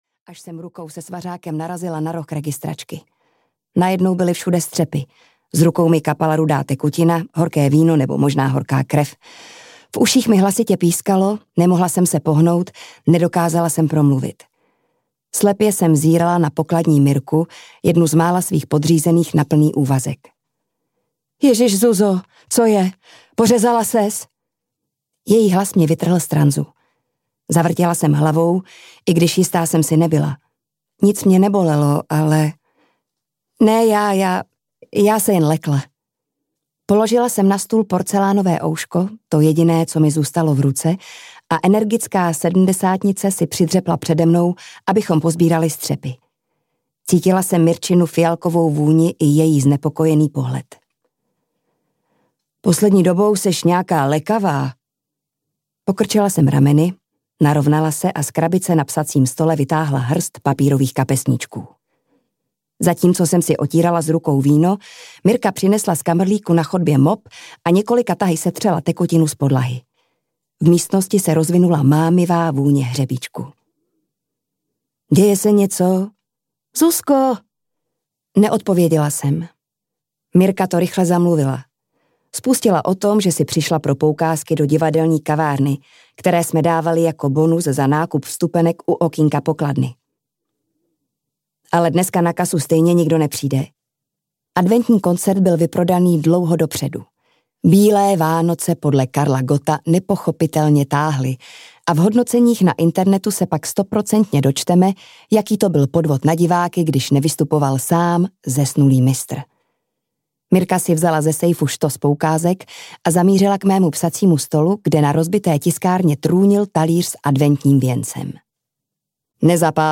Dravé zvěři napospas audiokniha
Ukázka z knihy